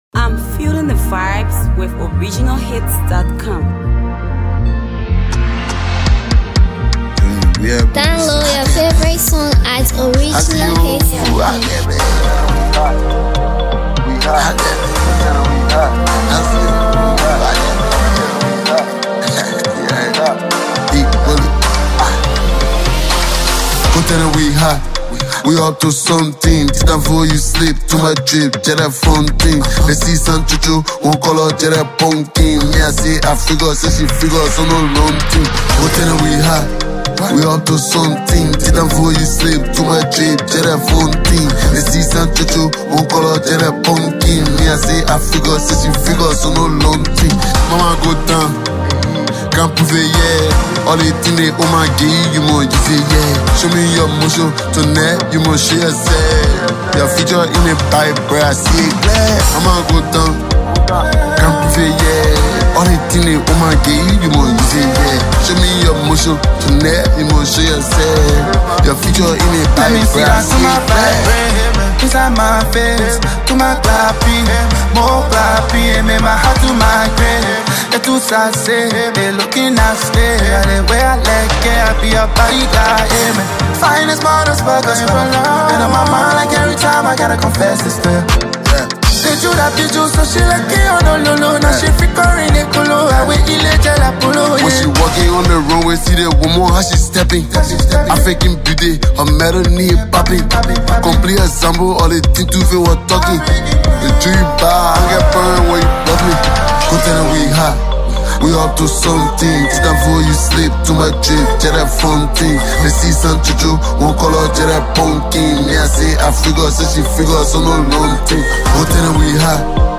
trap rapper